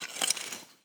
SFX_Harke_04_Solo_Reverb.wav